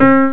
INTERACTIVE PIANO
the note should sound out.